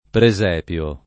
pre@$pLo] s. m.; pl. ‑pi (raro, alla lat., -pii) — lett. presepe [pre@$pe]: Beva ad un pozzo e mangi ad un presèpe [b%va ad um p1ZZo e mm#nJi ad um pre@$pe] (Pascoli); forma, questa, abbastanza com. in alcuni usi con sign. più o meno letterale e nella locuz. presepe vivente